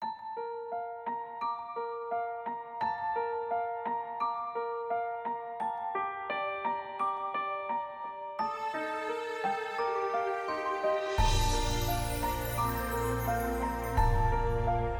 Musique multipistes.